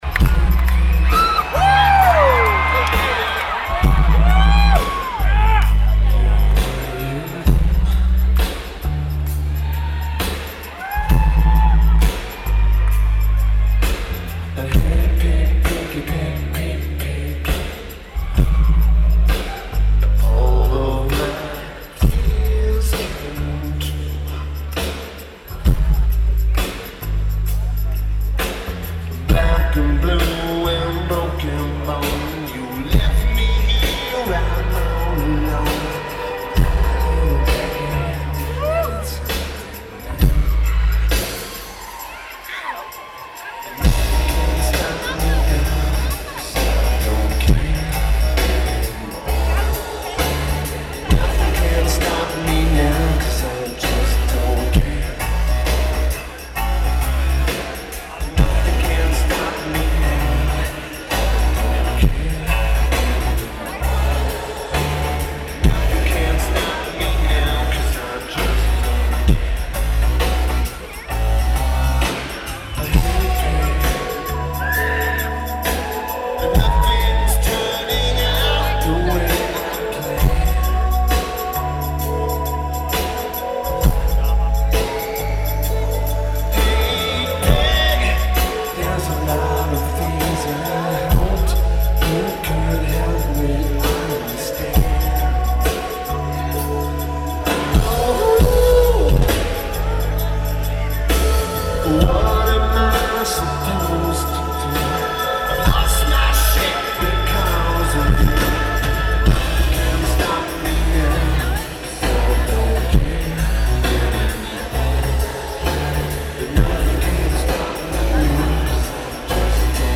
Rabobank Arena
Lineage: Audio - AUD (Roland R05 + Internal Mics)